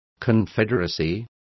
Complete with pronunciation of the translation of confederacy.